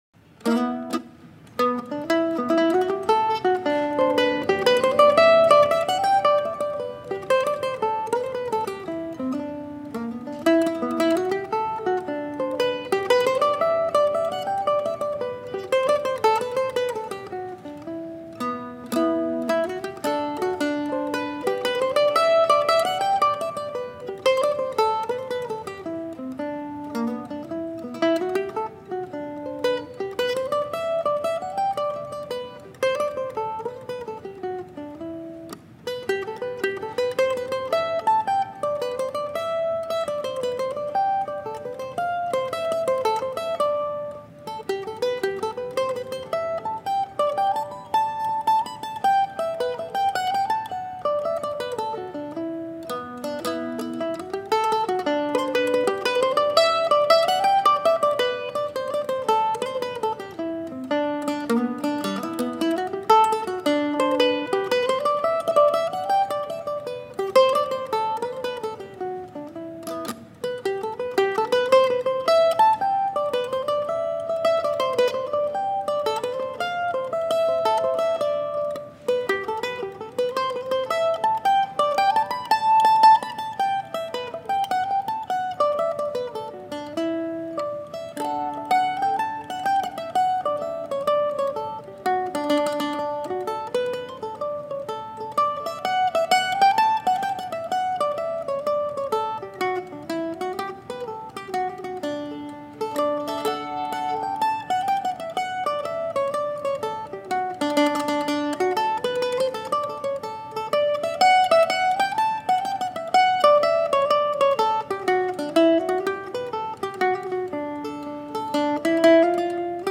Swarbrick Sends a Tune to Iowa (live at CMSA 2019)
Medley of solo mandolin tunes from the 2019 CMSA open mic concert on Oct. 10, 2019 in Normal, Illinois.
The sound was especially good in the room and I played a little better than usual.